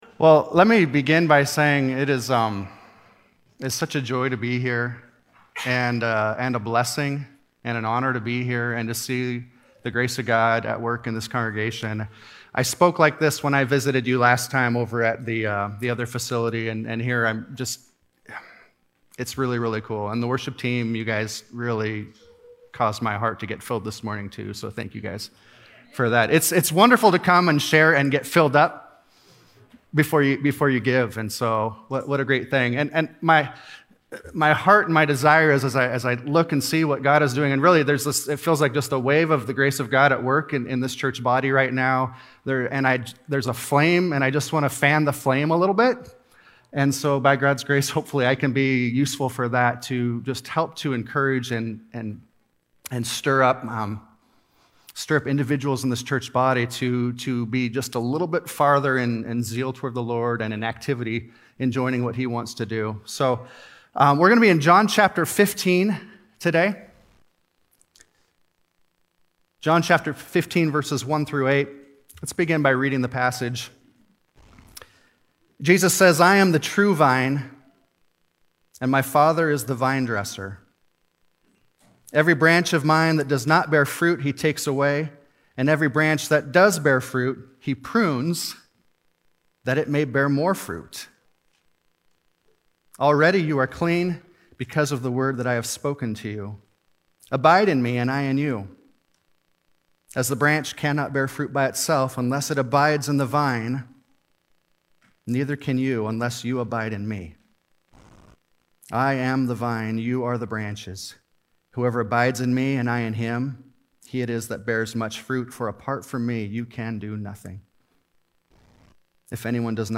A Call for Disciplemaking (John 15:1-8) - Guest Speaker